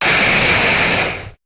Cri de Kyurem dans Pokémon HOME.